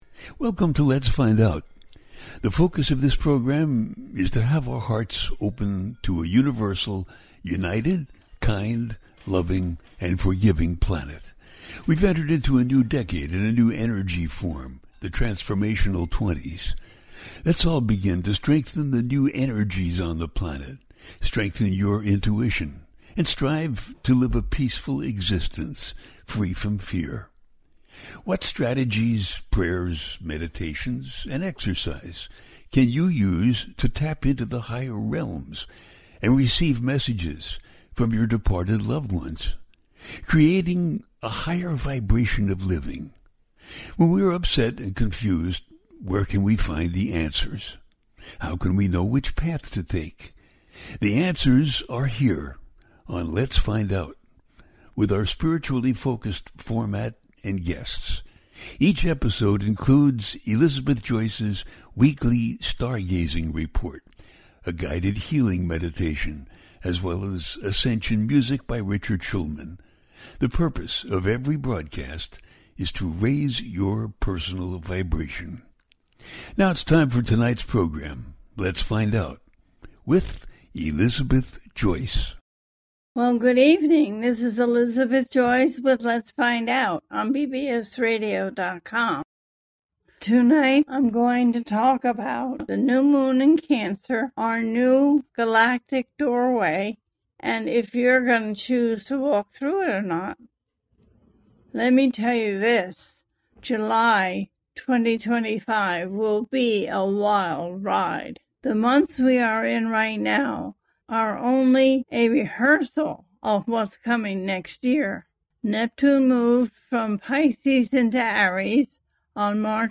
The New Moon in Cancer and the Galactic Door Opens - A teaching show